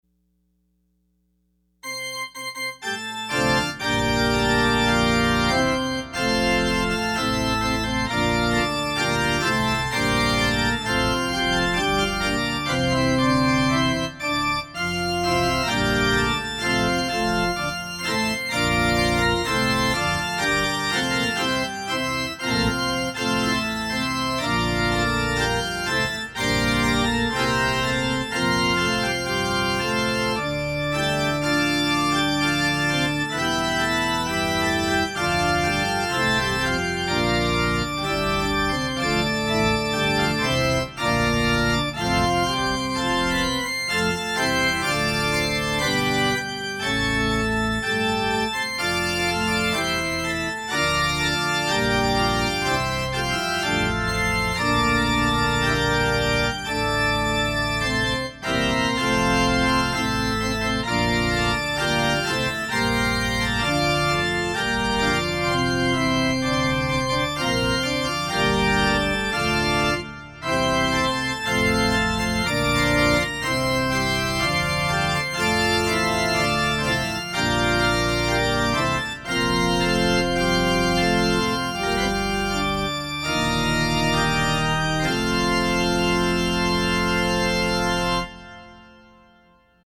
Opening Hymn – Gloria in Excelsis